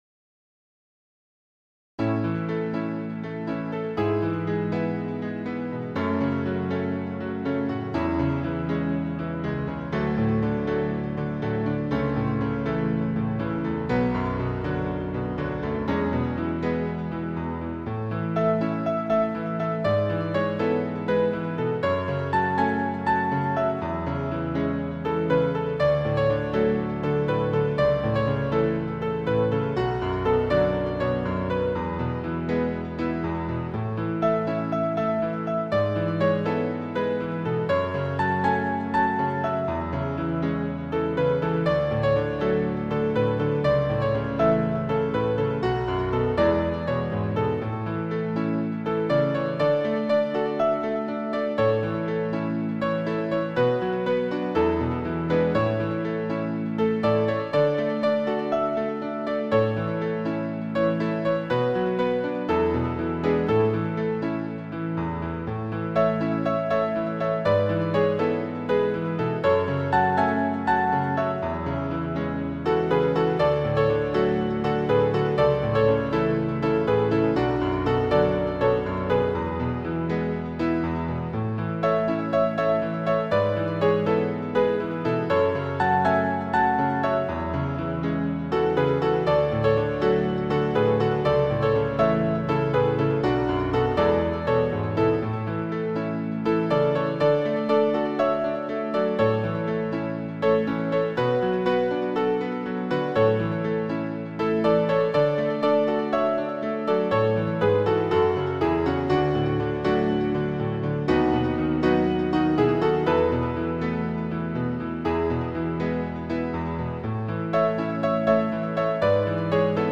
シンプルなピアノ曲です。